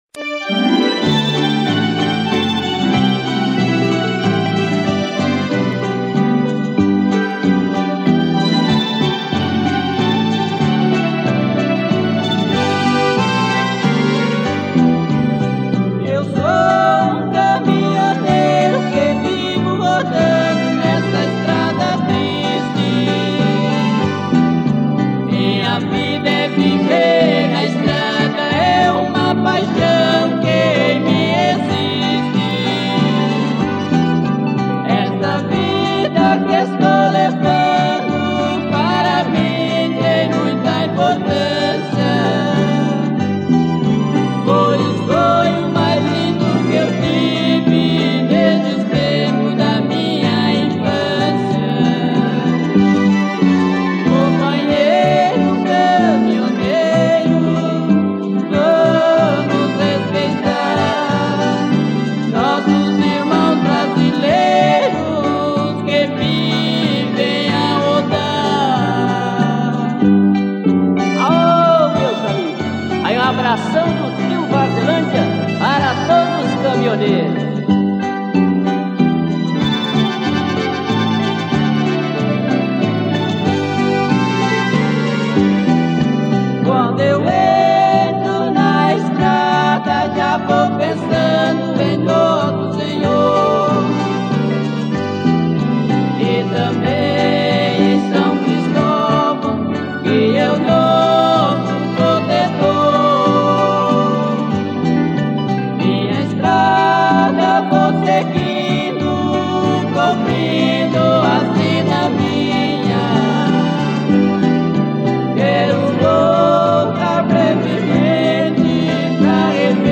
Sertanejo.